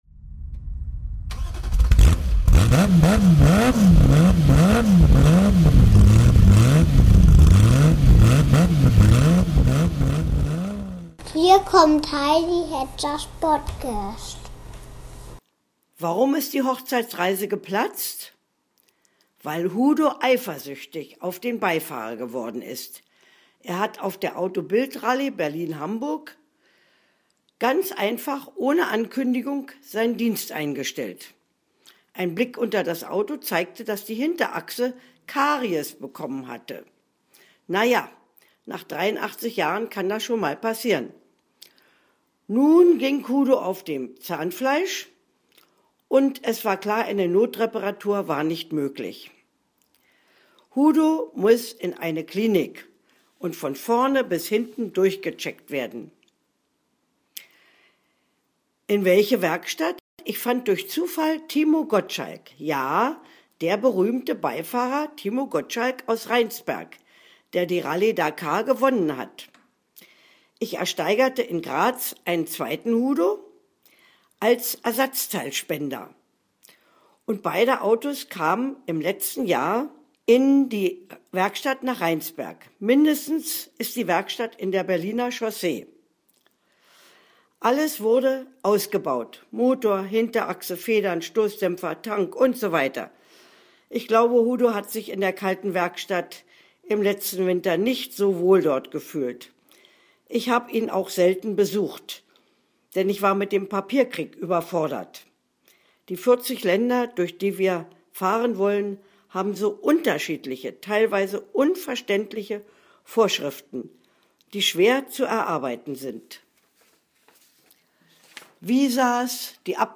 P.S. Man kann sich diesen Text auch von mir gesprochen anhören (das hört sich dann an, wie ein schlechtes Hörbuch.)